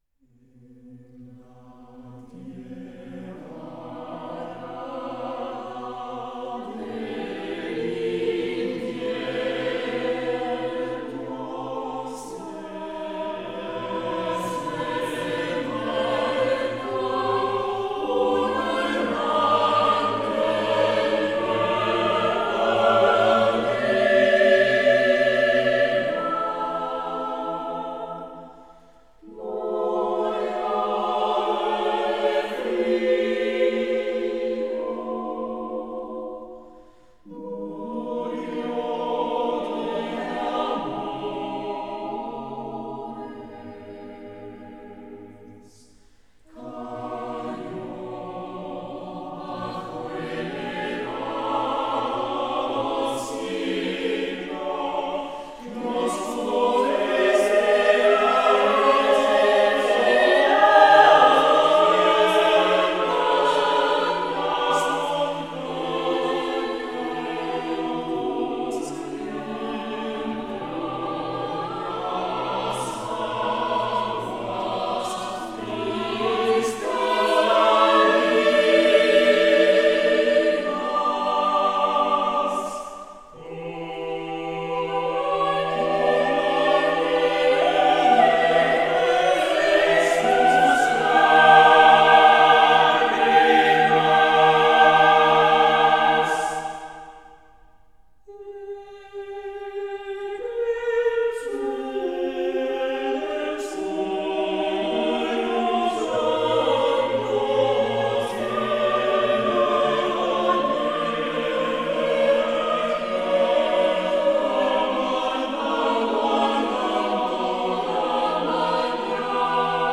En la tierra arada del invierno para coro